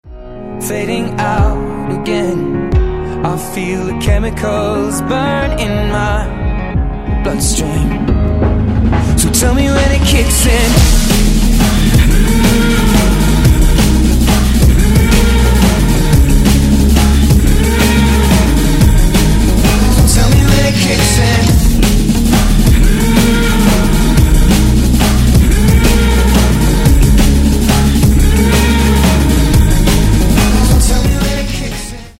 Клубные
Классный и быстрый
Метки: drum&bass,